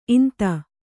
♪ inta